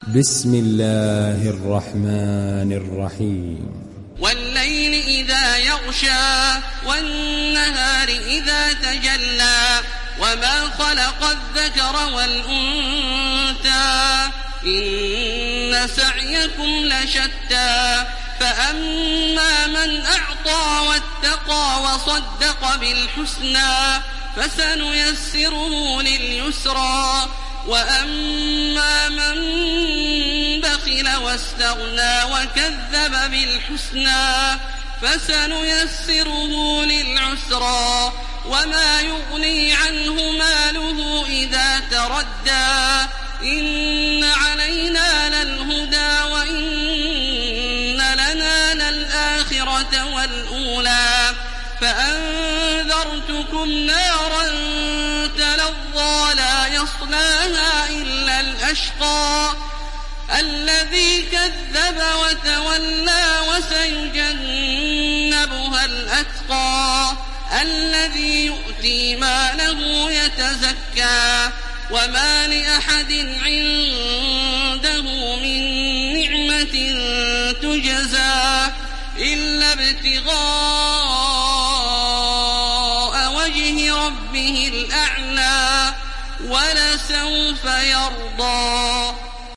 İndir Leyl Suresi Taraweeh Makkah 1430